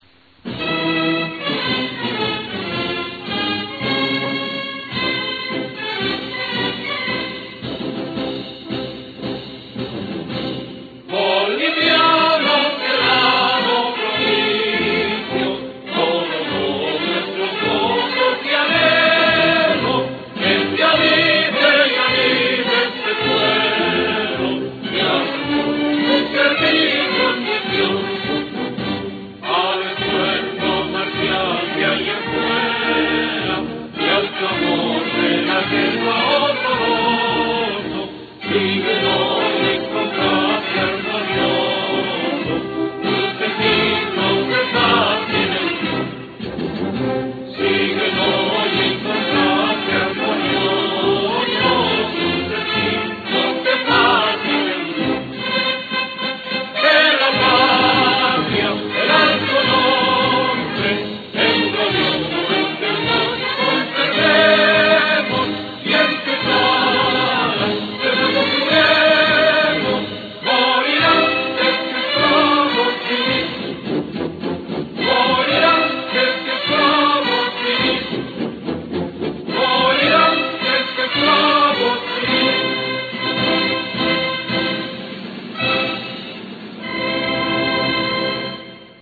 ボリビアの国歌 (合唱)